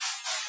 垃圾袋
描述：这是从垃圾桶中取出的垃圾袋的声音，瓶子敲着地板。
Tag: 垃圾桶 OWI 垃圾袋 运动 塑料 垃圾